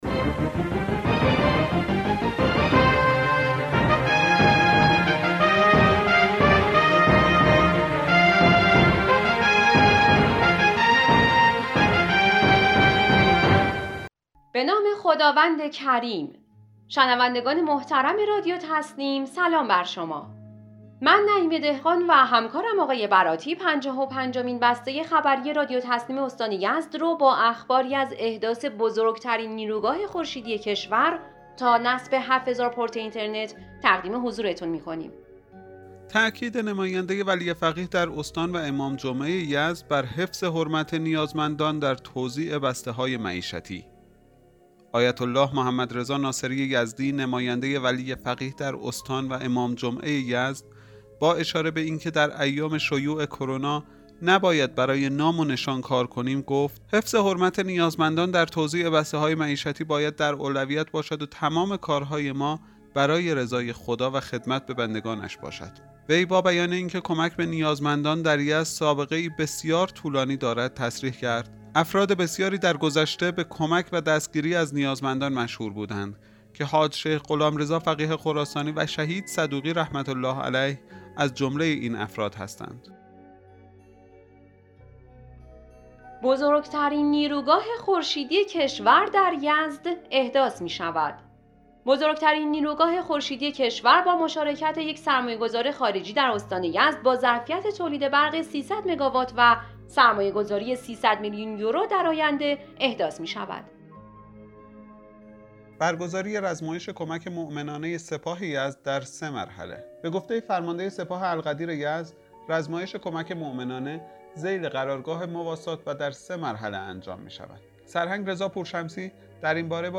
بسته خبری امروز رادیو تسنیم به اخبار متعددی از نقاط مختلف استان از جمله تاکید آیت الله ناصری یزدی بر حفظ حرمت نیازمندان در توزیع بسته‌های معیشتی و استقرار گمرک در بندر خشک پیشگامان اختصاص دارد.